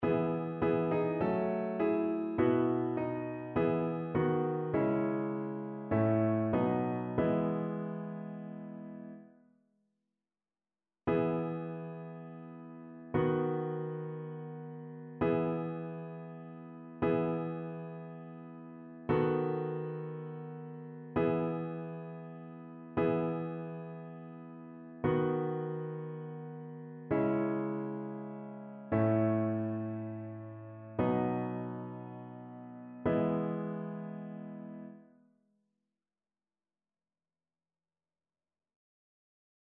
ChœurSopranoAltoTénorBasse